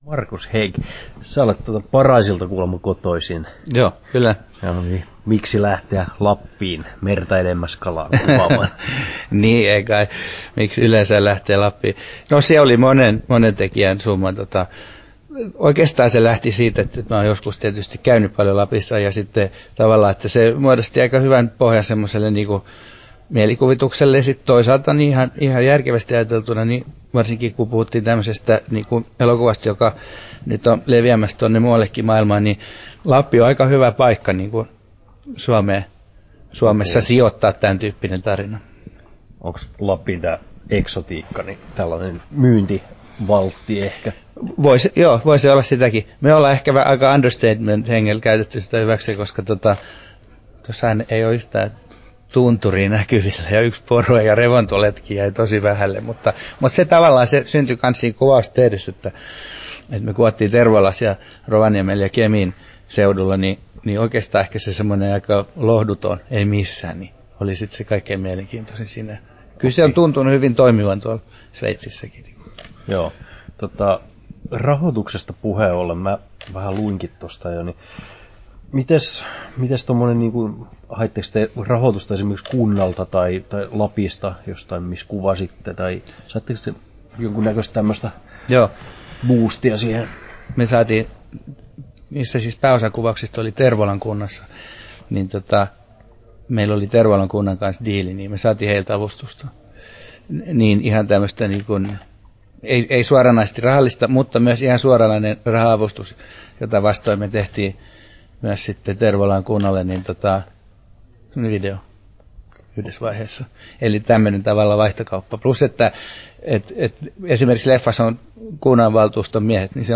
10'42" Tallennettu: 20.5.2014, Turku Toimittaja